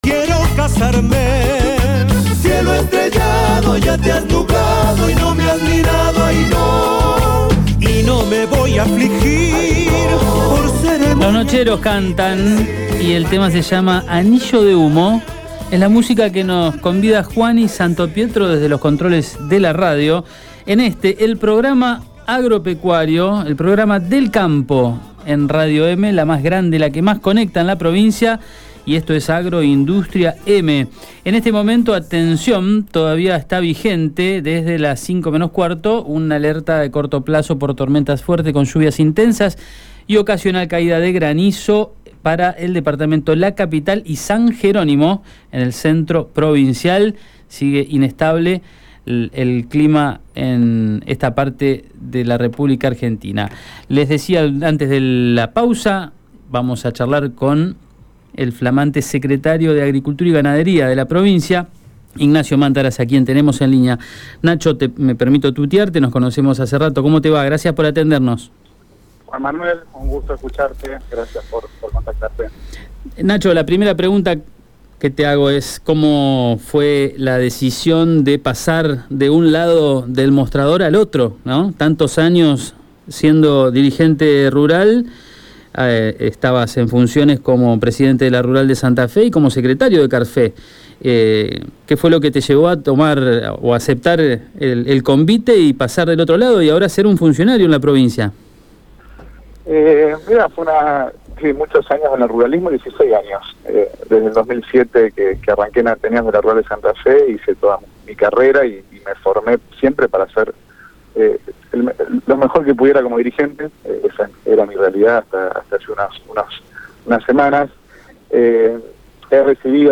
Escucha la palabra de Ignacio Mantaras en Radio EME: